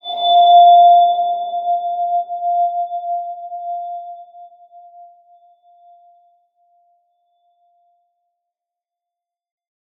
X_BasicBells-F3-pp.wav